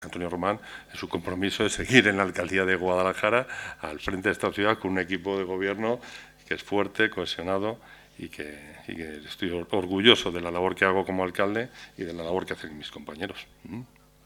Declaraciones de Antonio Román